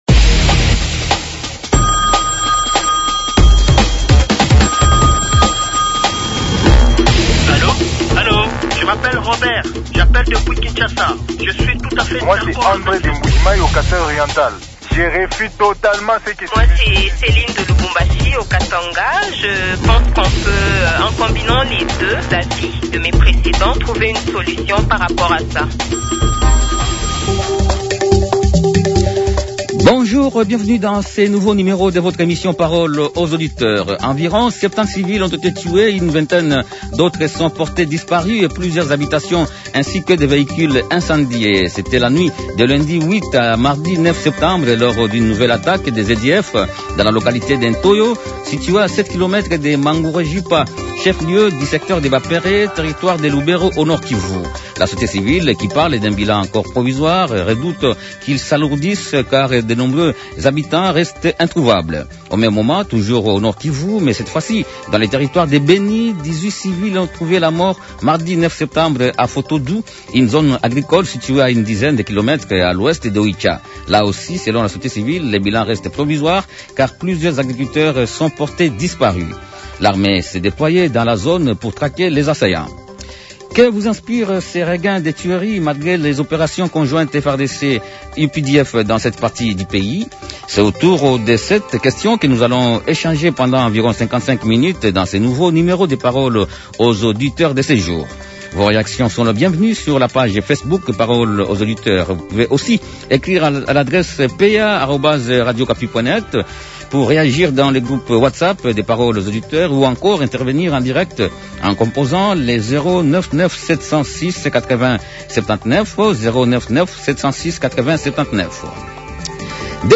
Les auditeurs ont échangé avec Carly Nzanzu Kasivita, député national élu du territoire de Beni, gouverneur honoraire de la province du Nord-Kivu et cadre de l’Union sacrée de la nation